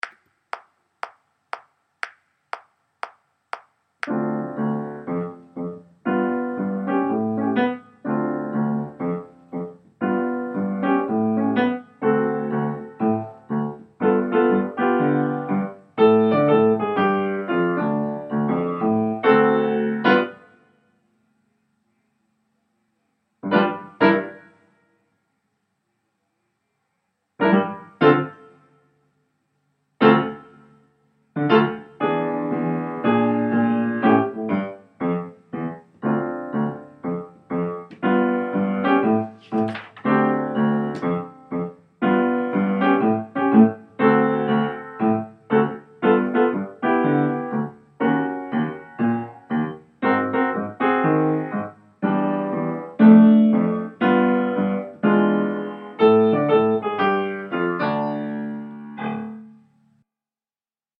No Dice Piano Only